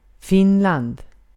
ملف تاريخ الملف وصلات معلومات الصورة (ميتا) Sv-Finland.ogg  (Ogg Vorbis ملف صوت، الطول 1٫4ث، 97كيلوبيت لكل ثانية) وصف قصير ⧼wm-license-information-description⧽ Sv-Finland.ogg English: Pronunciation of word Finland in Swedish. Female voice. Speaker from Gotland, Sweden.